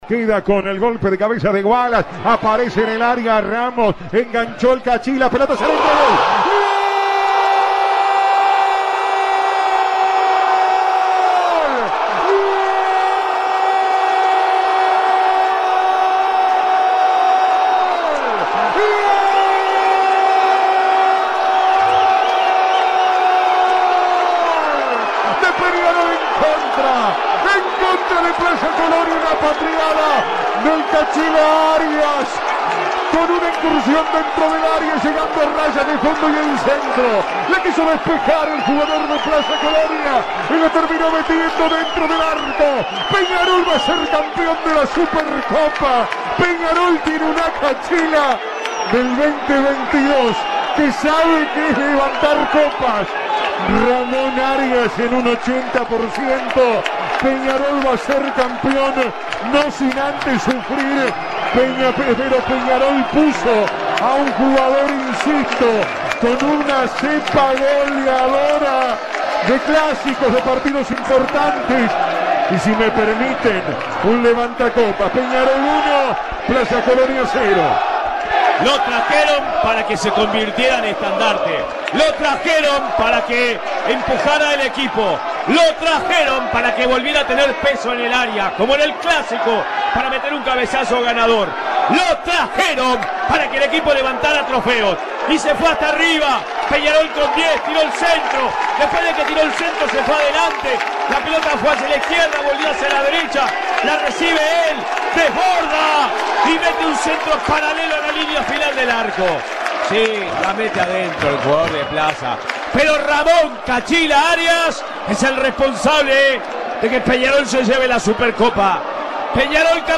Reviví el gol junto al equipo de Vamos que vamos: